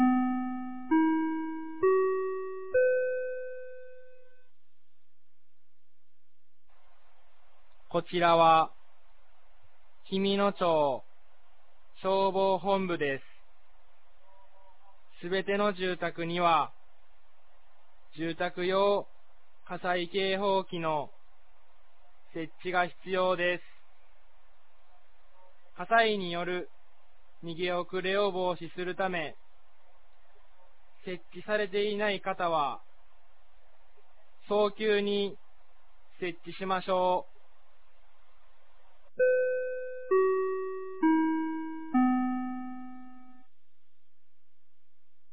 2023年08月12日 16時00分に、紀美野町より全地区へ放送がありました。